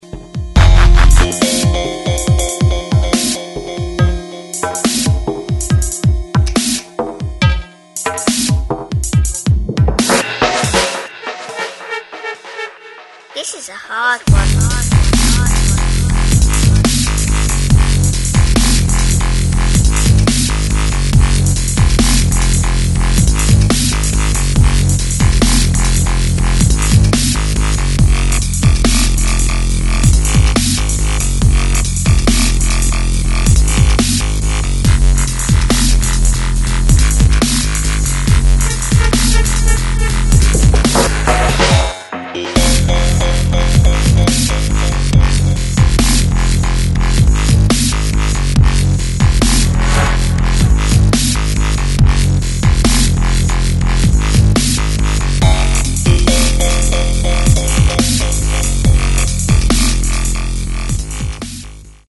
Styl: Dub/Dubstep